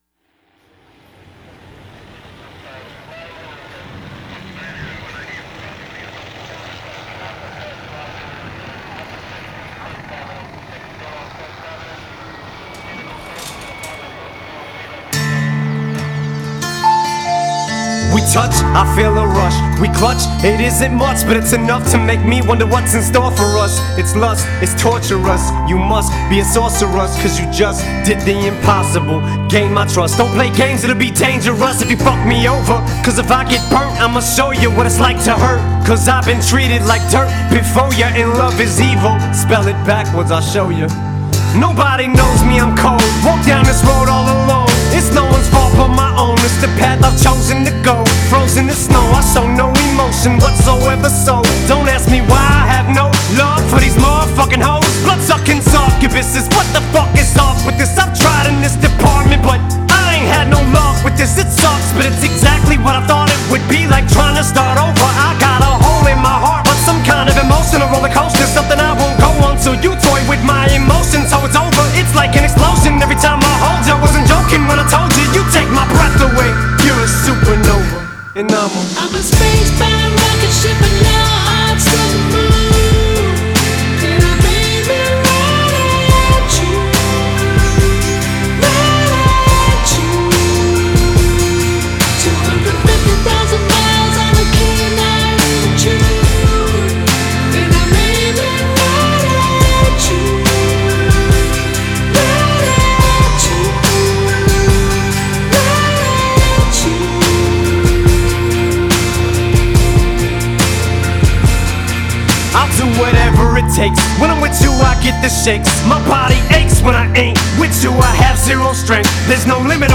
Rap/Hip-hop [19]